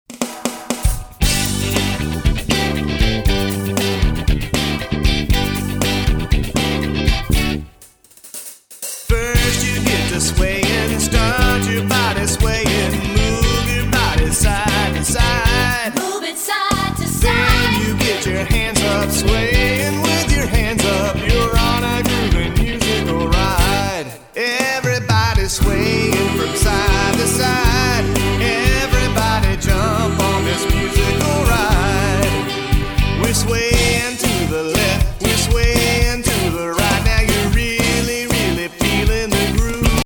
Filled from beginning to end with rockin’ movement tunes